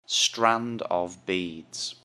strand-of-beads.mp3